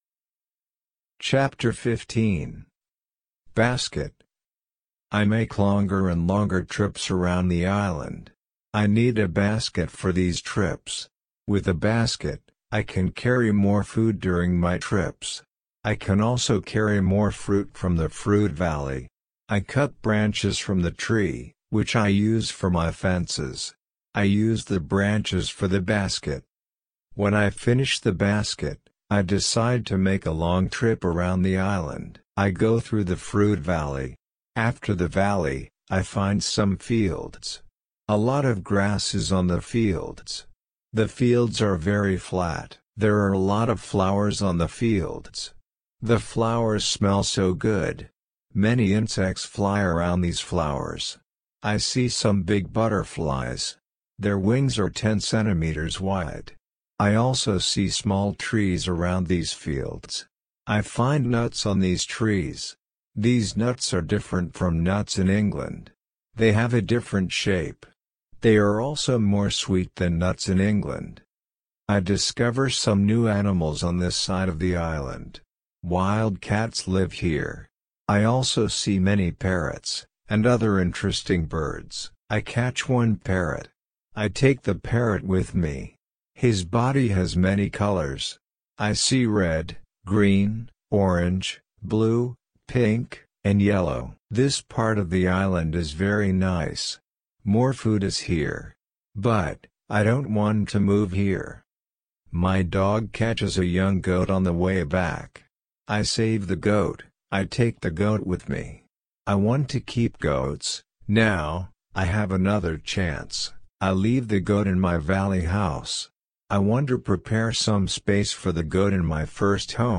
RC-L1-Ch15-slow.mp3